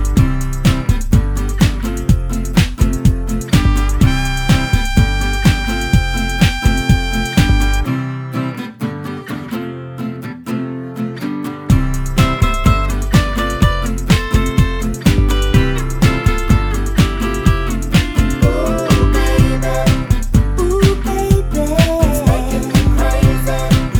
For Solo Male Pop (1990s) 3:46 Buy £1.50